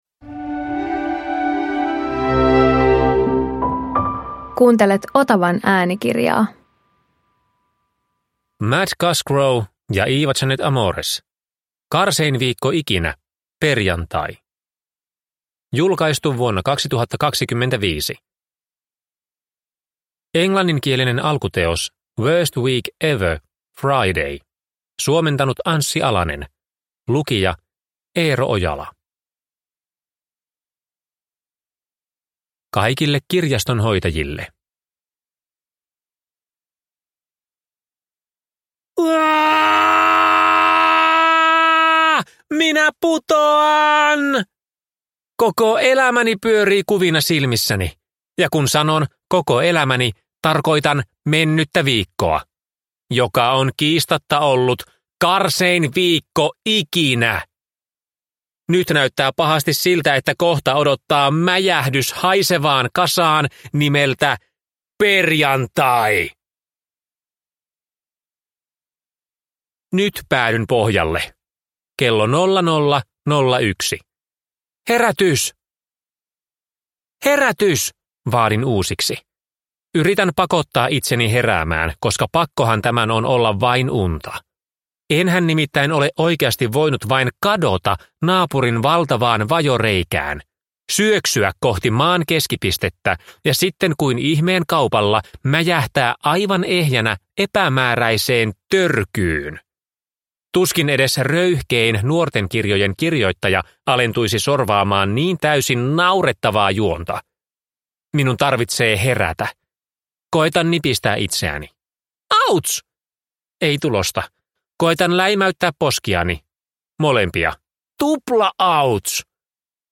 • Ljudbok